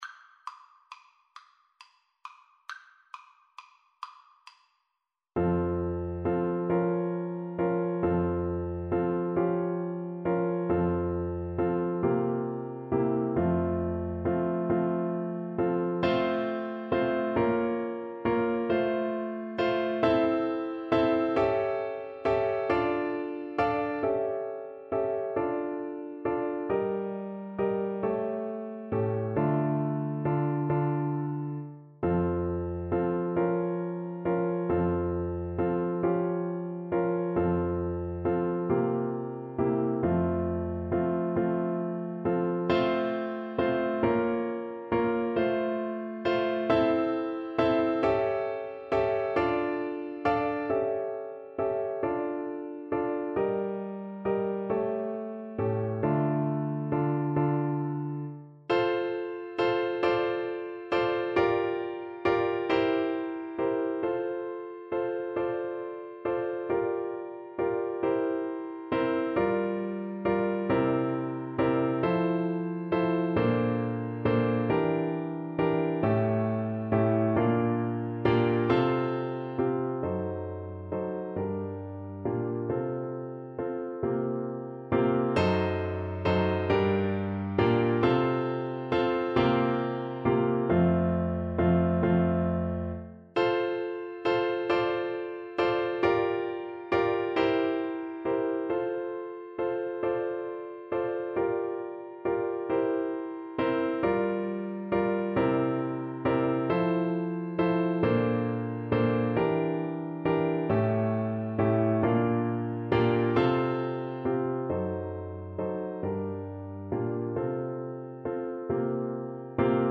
Classical Paradis, Maria Theresia von Sicilienne Flute version
Play (or use space bar on your keyboard) Pause Music Playalong - Piano Accompaniment Playalong Band Accompaniment not yet available transpose reset tempo print settings full screen
6/8 (View more 6/8 Music)
F major (Sounding Pitch) (View more F major Music for Flute )
Andantino .=c.45 (View more music marked Andantino)
Classical (View more Classical Flute Music)